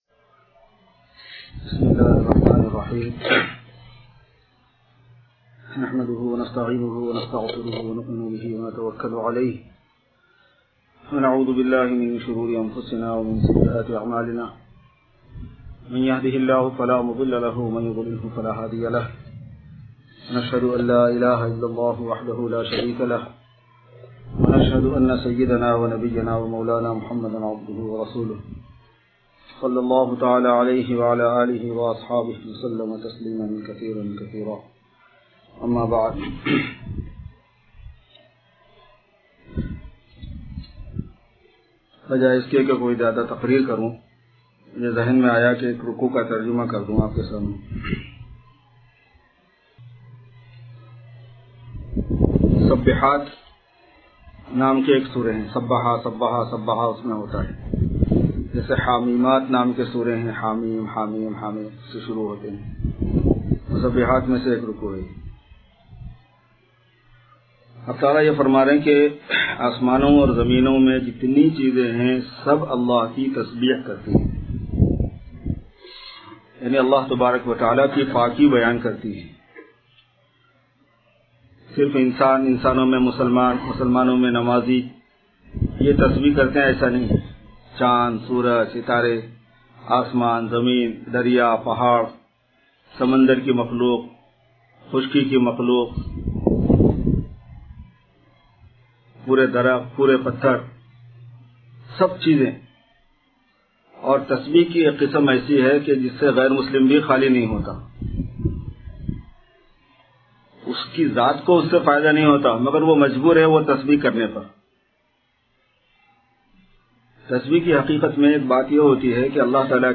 Khatm e Quran Bayan Ramadan 24-June-2016